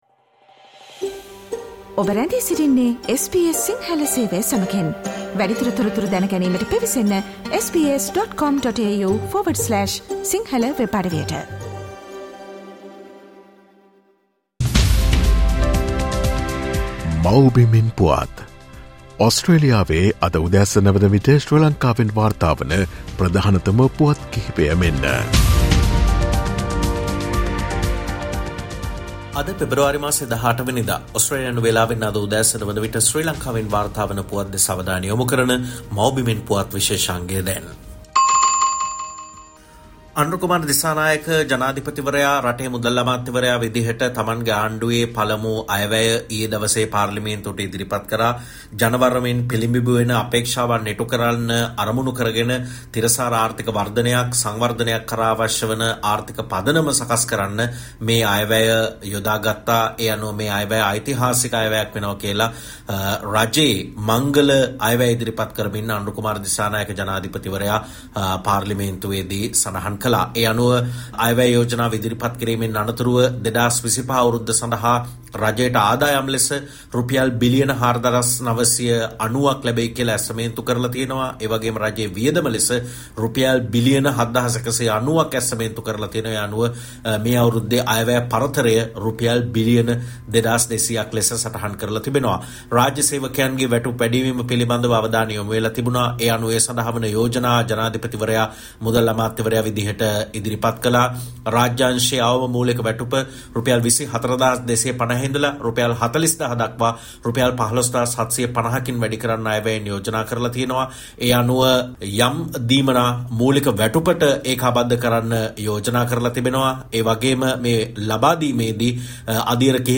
SBS Sinhala featuring the latest news reported from Sri Lanka - Mawbimen Puwath Share